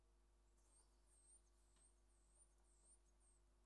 2 種類または３種類の要素音を２〜3個を セットに してこれを４から７セット鳴くのをすべてサエズリとしたが同じ組み合わせでも全く違う状況で鳴くものがあった。
羽ばたきと同時に波のような声で鳴くが必ずやや低音の短い波が加わる。
波といっても震えに近く営巣中の波のよう音声ではない(図−14)。